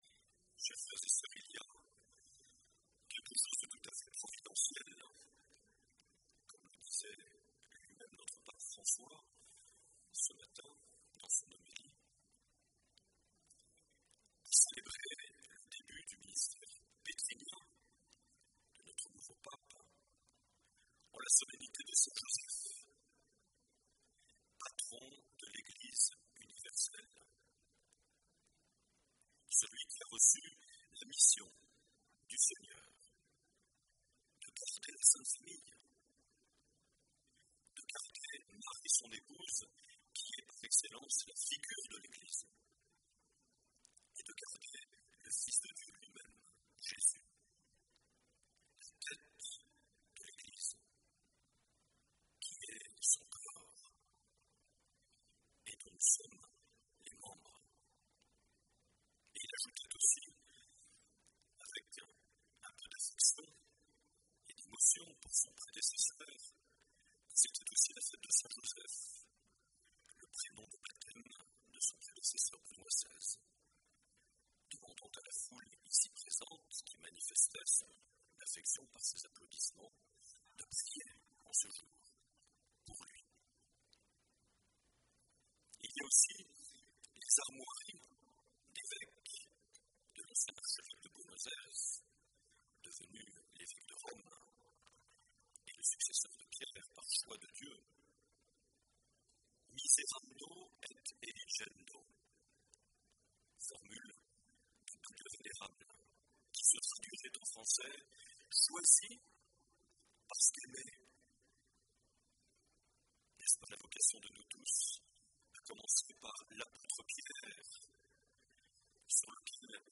19 mars 2013 - Cathédrale de Bayonne - Messe d’action de grâces pour l’élection du Pape François
Accueil \ Emissions \ Vie de l’Eglise \ Evêque \ Les Homélies \ 19 mars 2013 - Cathédrale de Bayonne - Messe d’action de grâces pour (...)
Une émission présentée par Monseigneur Marc Aillet